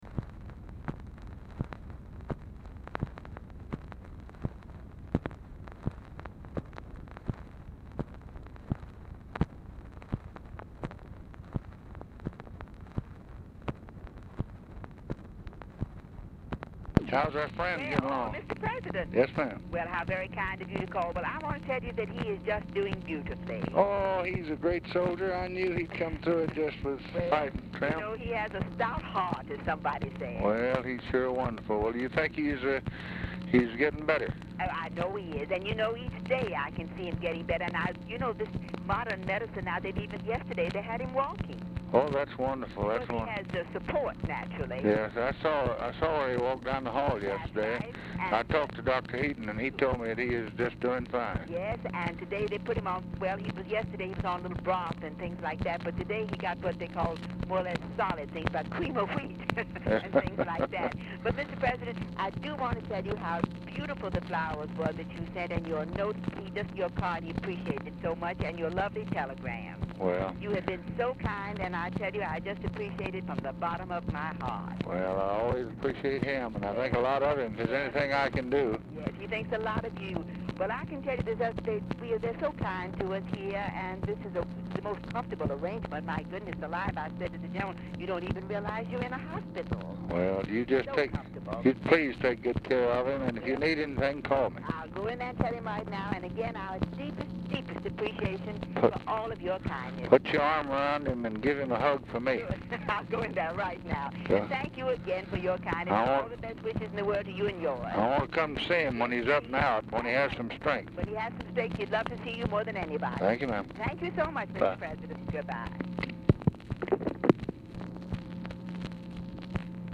Telephone conversation # 2448, sound recording, LBJ and JEAN MACARTHUR, 3/10/1964, 4:01PM
Dictation belt
Oval Office or unknown location